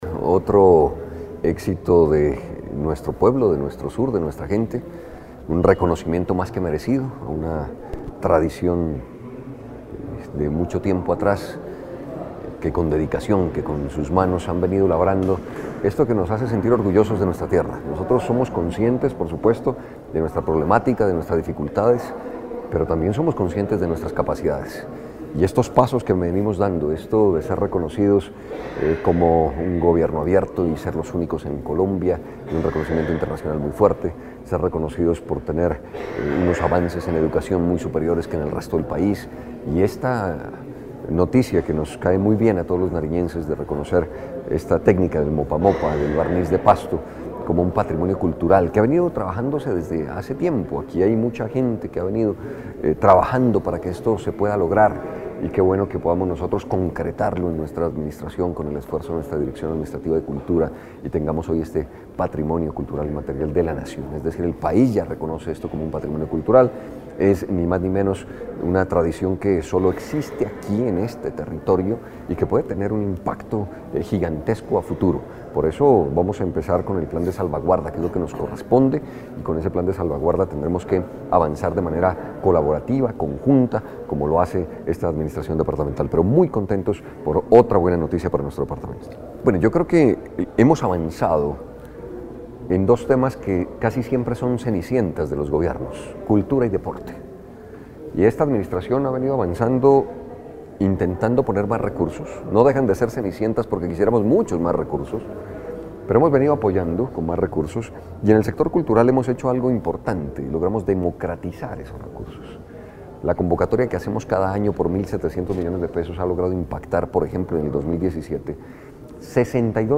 CAMILO_ROMERO_GALEANO_-_GOBERNADOR_DE_NARIO.mp3